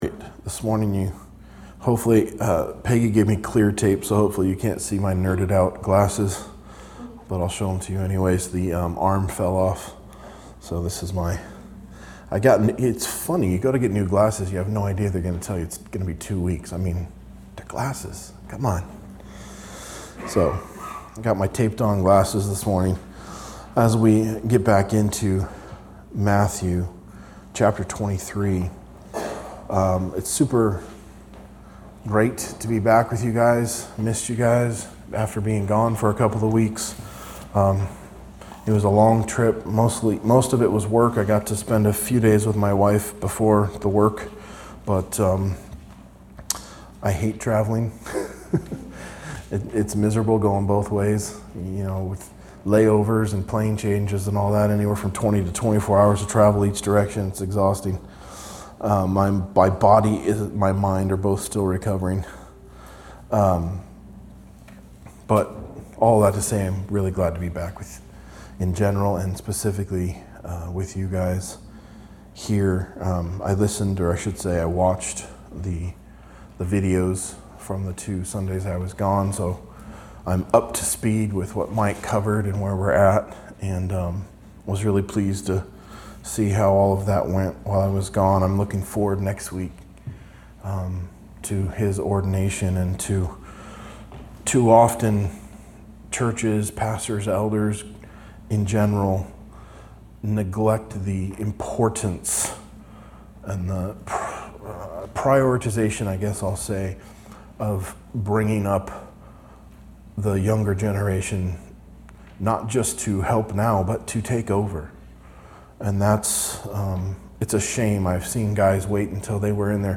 A message from the series "Matthew." Matthew 24:36-51